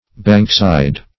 Bankside \Bank"side`\, n. The slope of a bank, especially of the bank of a stream.